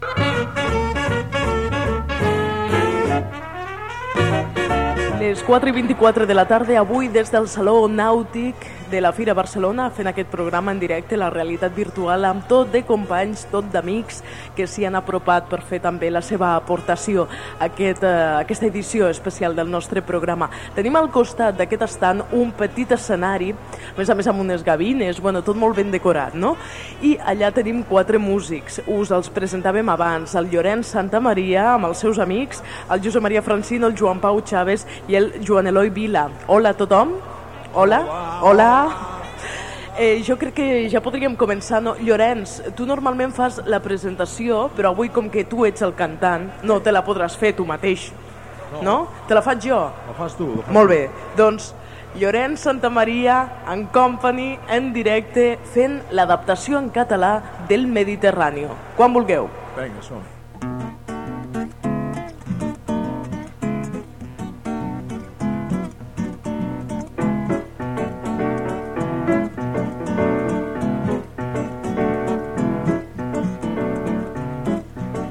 Hora, presentació d'una edició especial des del Saló Nàutic de Barcelona, amb el cantant Llorenç Santamaría i altres músics
Entreteniment
FM